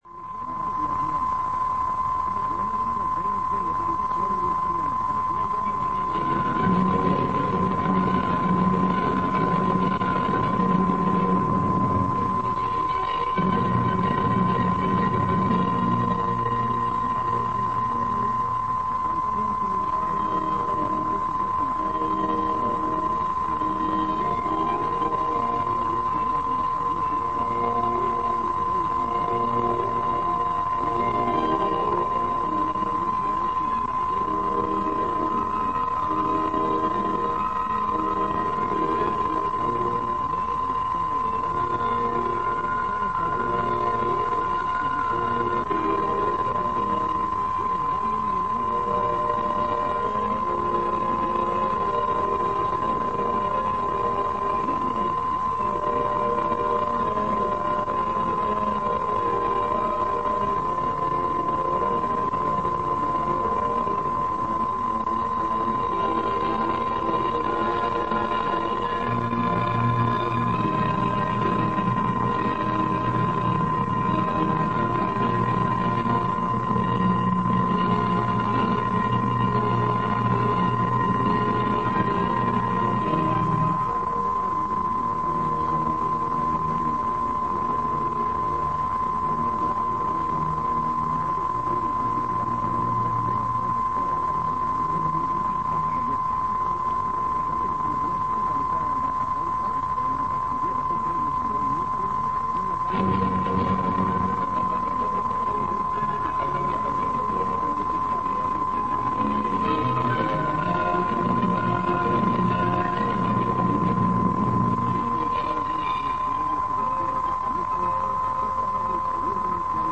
Zene
rossz hangminőség